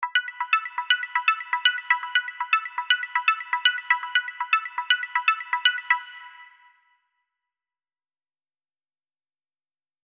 Call_Outgoing.342df6dee84796e8c083.mp3